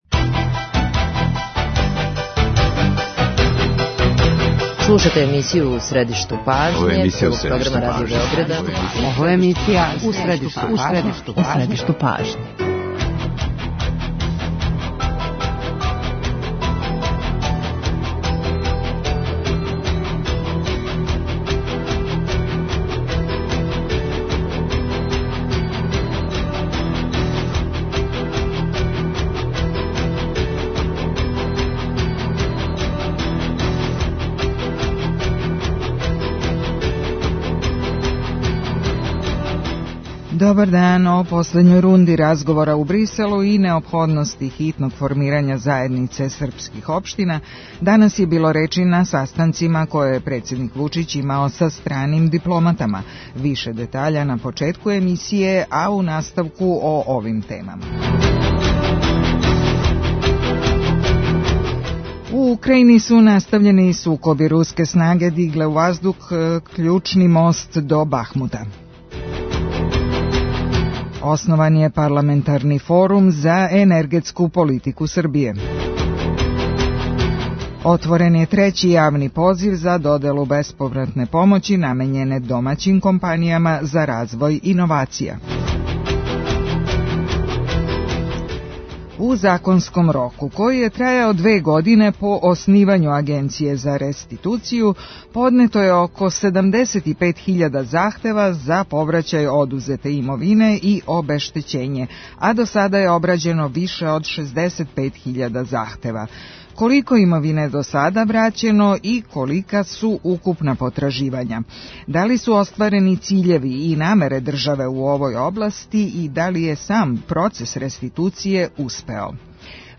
Гост емисје је Страхиња Секулић, директор Агенције за реституцију.